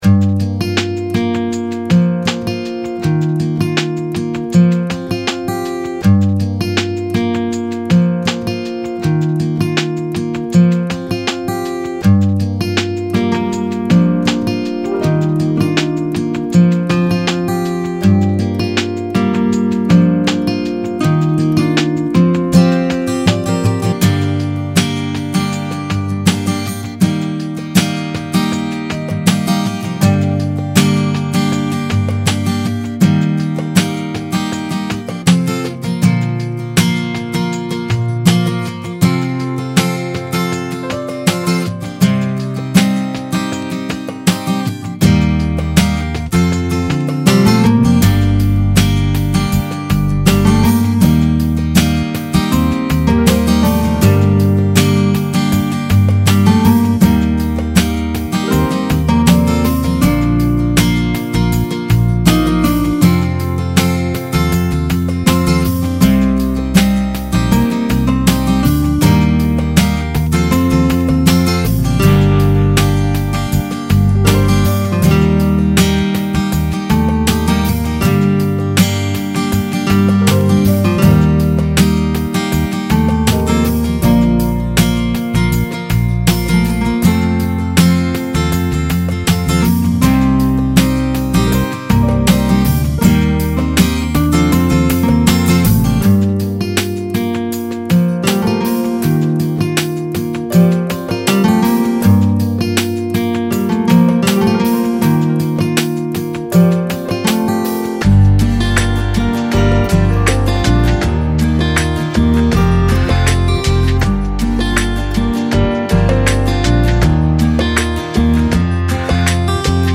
Largo [40-50] nostalgie - guitare acoustique - - -